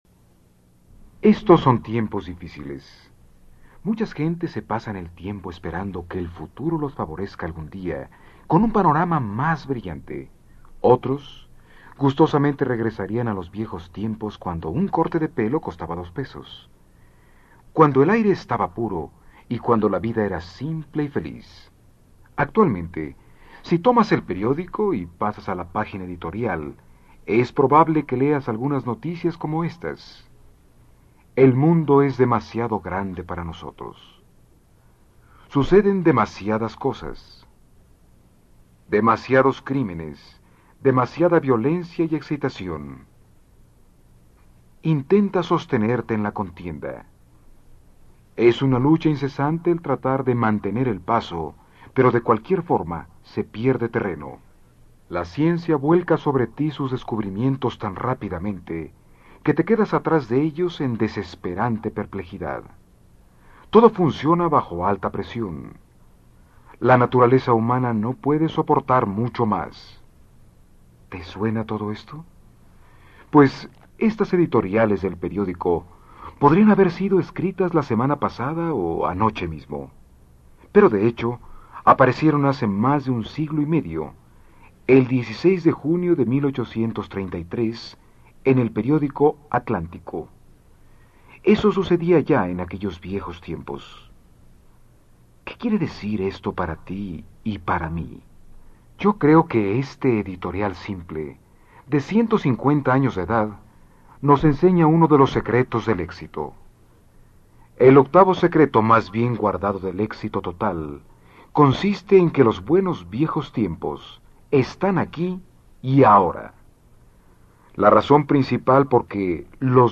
Audio Libros